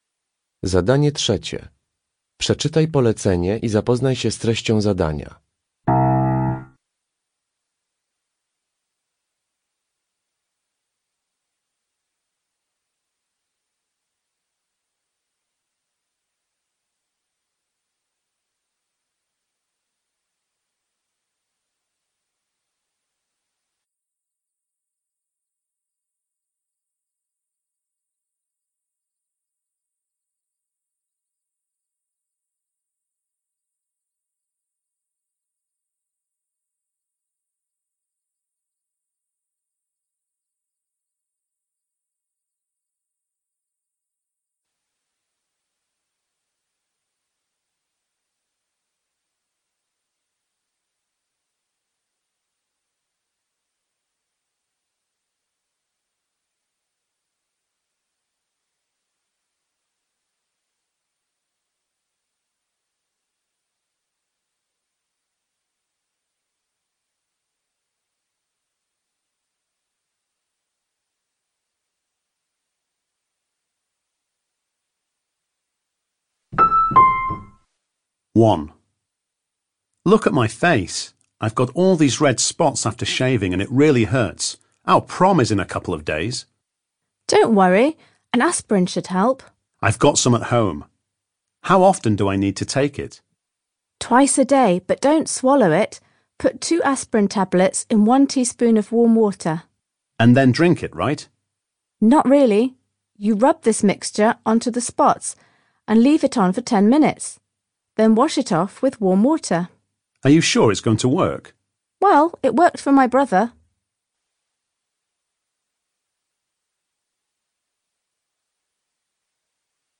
Zadanie 2. (0–4) Uruchamiając odtwarzacz z oryginalnym nagraniem CKE usłyszysz dwukrotnie cztery wypowiedzi związane z uśmiechem.